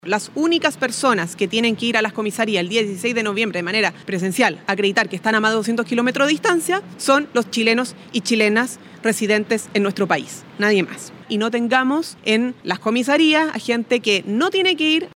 La ministra vocera de Gobierno, Camila Vallejo, dijo que a idea es que el domingo 16 de noviembre no existan filas innecesarias en las comisarías, lo que podría entorpecer el proceso.